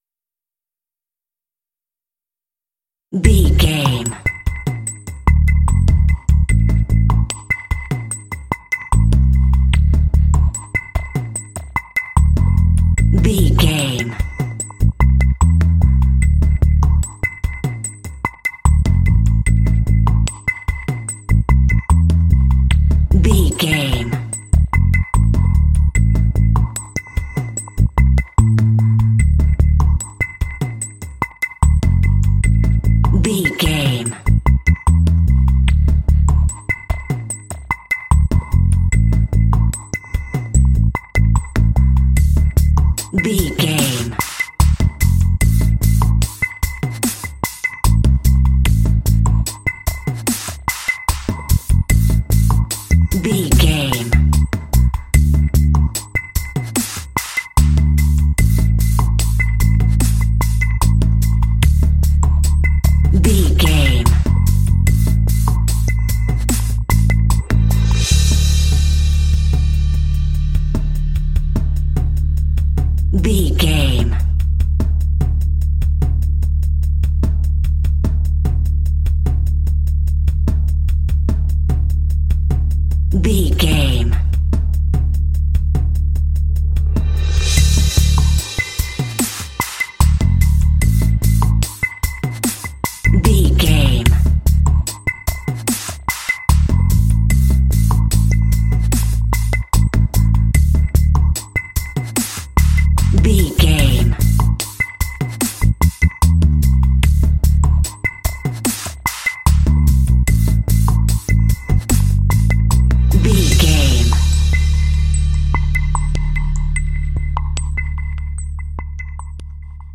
Light and suspenseful, ideal for adventure games.
Aeolian/Minor
suspense
sad
dramatic
bass guitar
drums
contemporary underscore